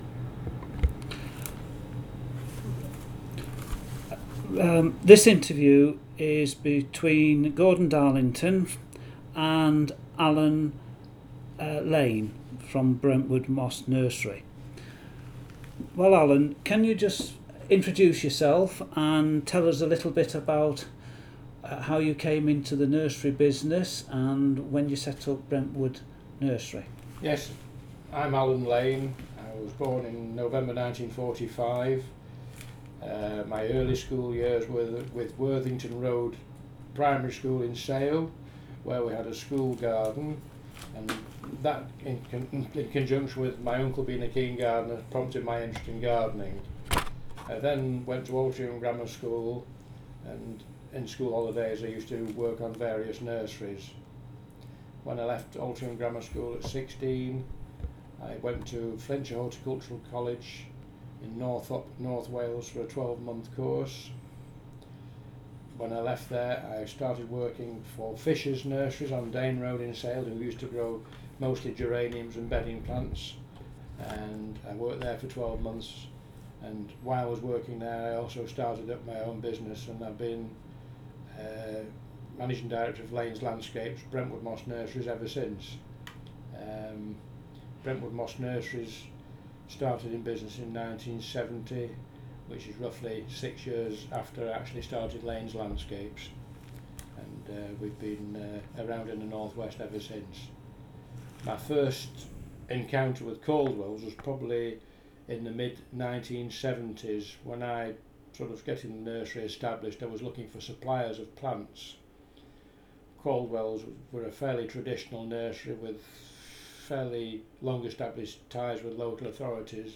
Caldwell Archives - Oral History